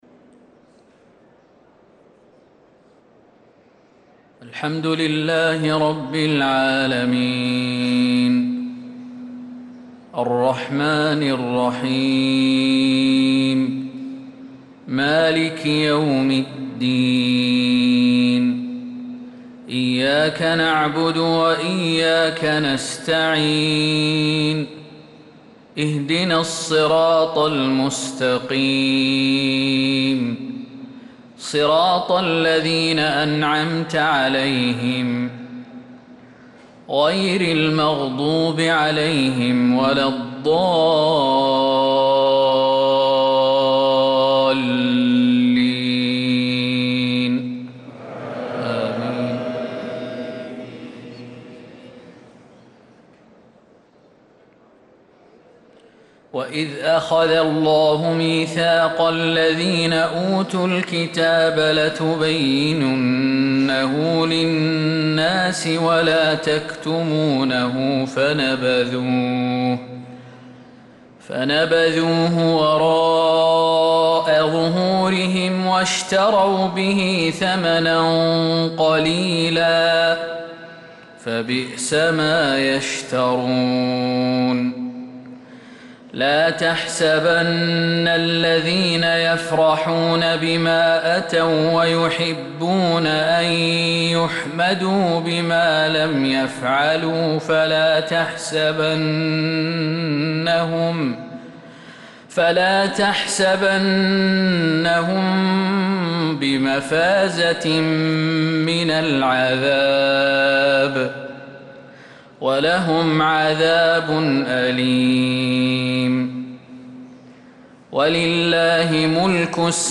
صلاة الفجر للقارئ خالد المهنا 4 جمادي الأول 1446 هـ
تِلَاوَات الْحَرَمَيْن .